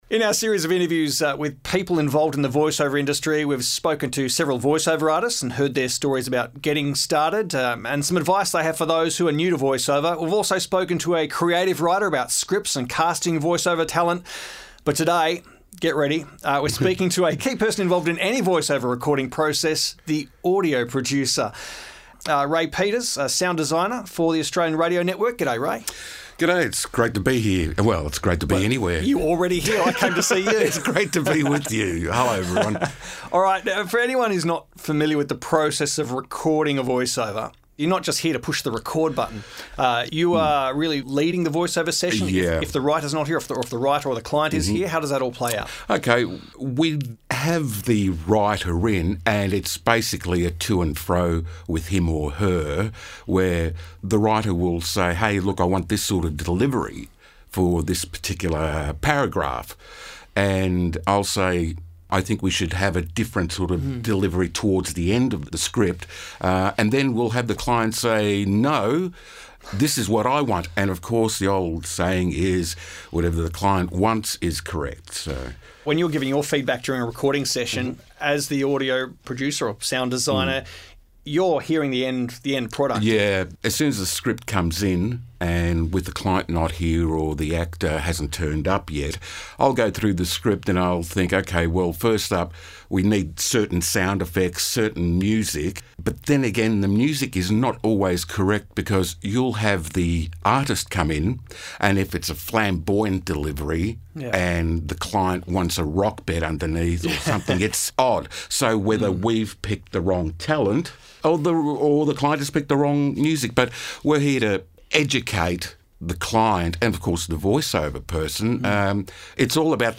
Transcription: Interview with Sound Designer